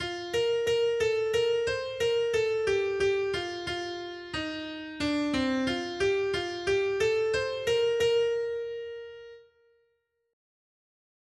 Noty Štítky, zpěvníky ol573.pdf responsoriální žalm Žaltář (Olejník) 573 Skrýt akordy 1.